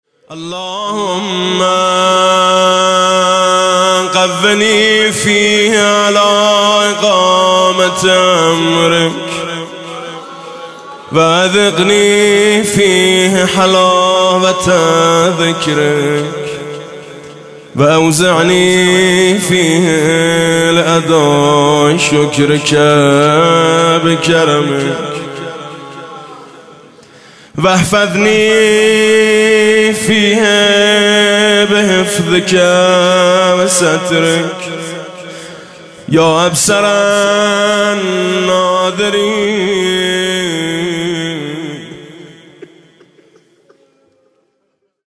19 اردیبهشت 98 - هیئت میثاق با شهدا - دعای روز چهارم ماه مبارک رمضان